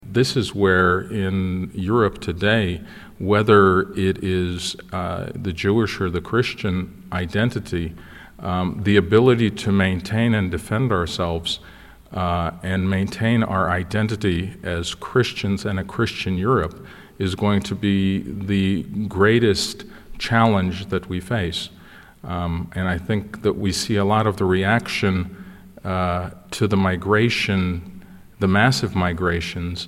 Arcybiskup metropolita wileński Gintaras Grusas (na zdjęciu z prawej) bierze udział w debacie na temat kryzysu migracyjnego, która rozpoczęła się w Katolickim Uniwersytecie Lubelskim w ramach V Kongresu Kultury Chrześcijańskiej.